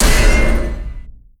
shield break Meme Sound Effect
shield break.mp3